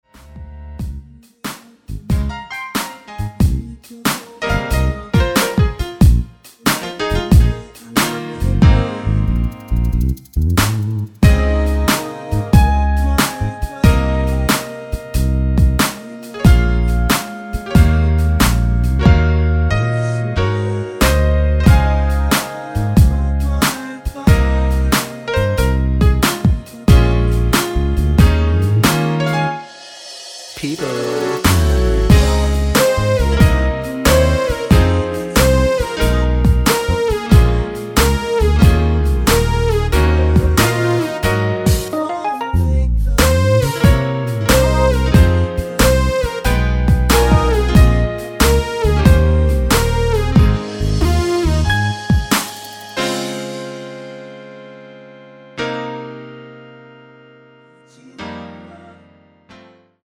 (-1) 내린 코러스 포함된 MR 입니다.(미리듣기 참조)
Ab
◈ 곡명 옆 (-1)은 반음 내림, (+1)은 반음 올림 입니다.
앞부분30초, 뒷부분30초씩 편집해서 올려 드리고 있습니다.